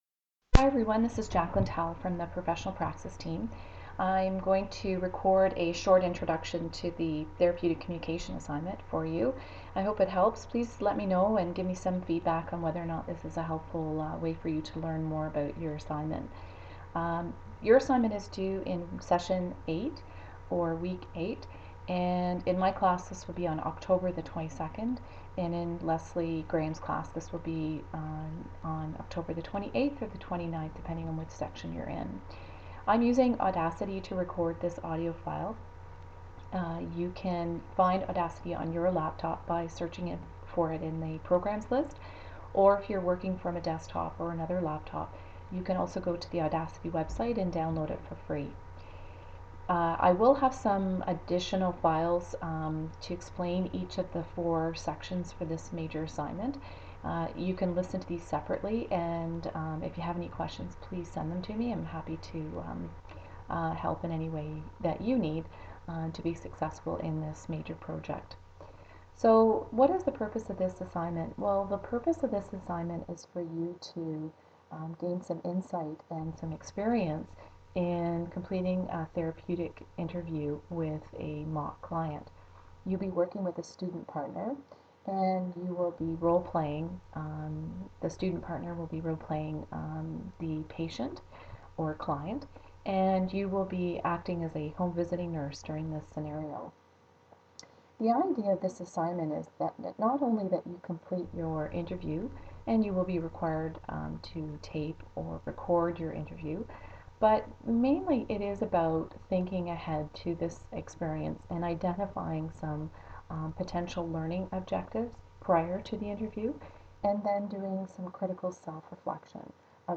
For example: The following is an auditory description of an assignment that is also posted as a Word document on a course site.